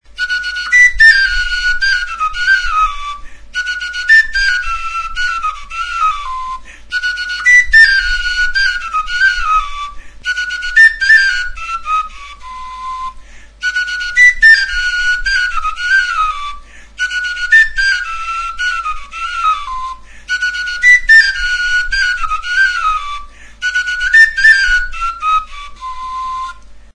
Instruments de musiqueTXIFLOA
Aérophones -> Flûtes -> Á bec (á deux mains) + kena
Intsusa makilarekin egindako bi eskuko flauta zuzena da.